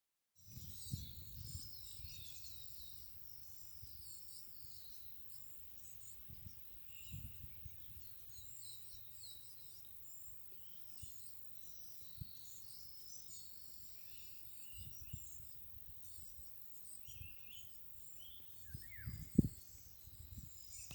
Coal Tit, Periparus ater
Administratīvā teritorijaOlaines novads
StatusRecently fledged young (nidicolous species) or downy young (nidifugous species)